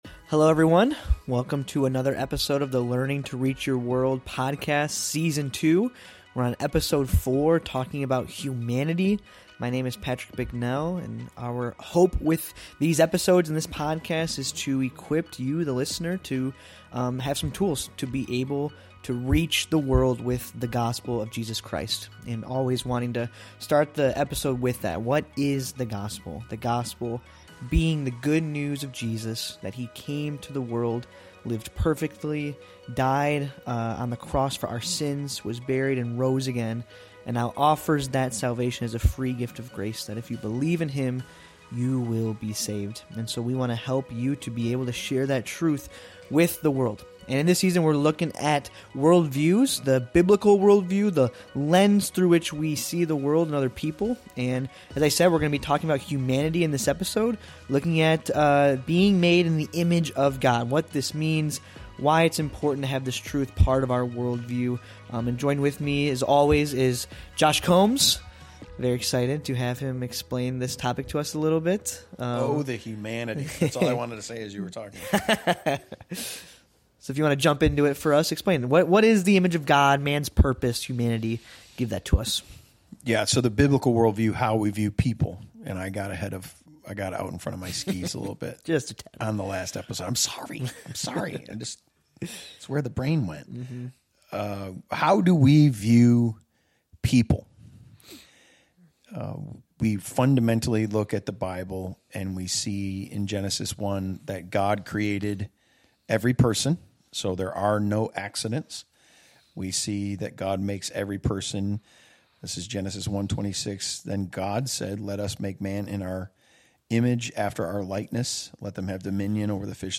In Season Two, Episode Four of the Learning to Reach Your World podcast, the hosts focus on humanity as a central component of a biblical worldview.
Drawing from personal experiences and historical examples, the hosts explain how Scripture has been misused in the past to justify racism and slavery through distorted interpretations of passages like the mark of Cain or Noah’s curse.